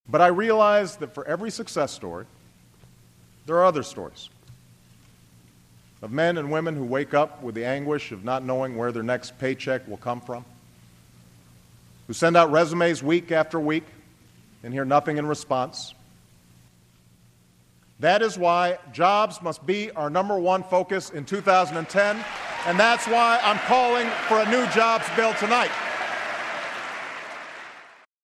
this portion of President Obama’s State of the Union address on Wednesday night was so galling.